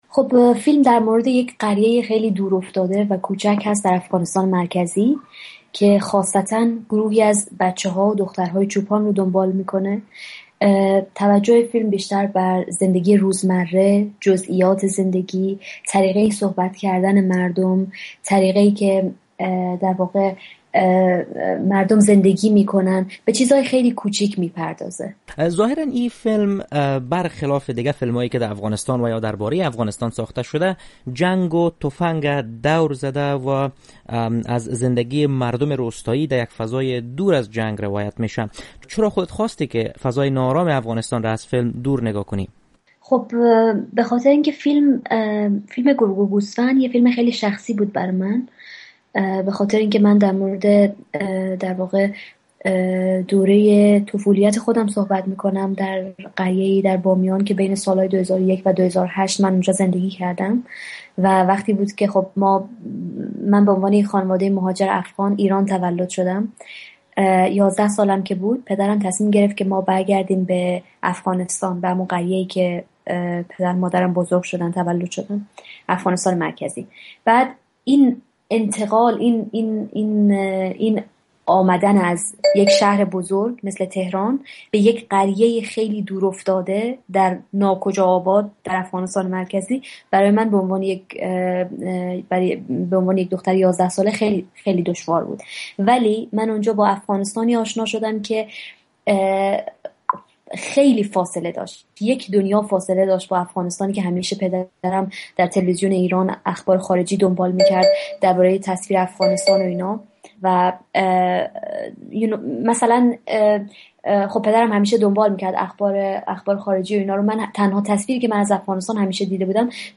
مصاحبه با شهربانو سادات برندهء جایزهء کن "دو هفته با کارگردانان"